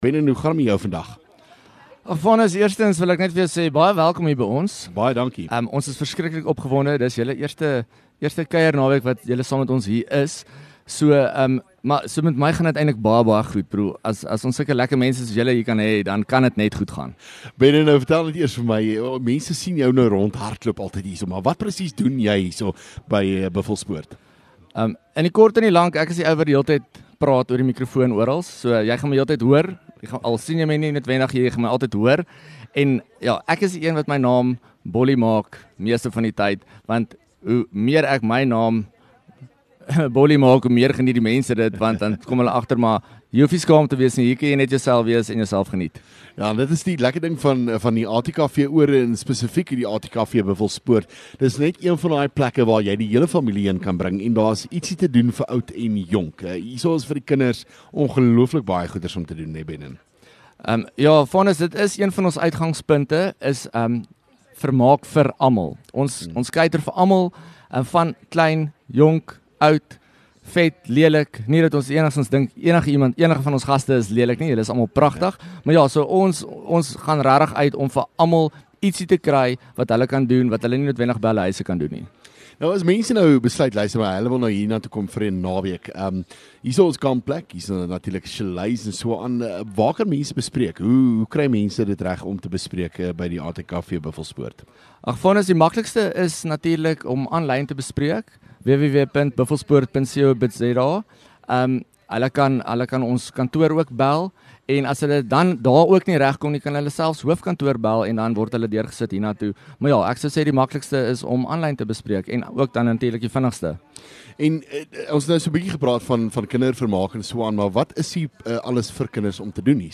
LEKKER FM | Onderhoude 28 Feb ATKV Bufflespoort